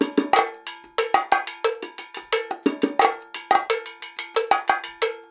90 BONGO 6.wav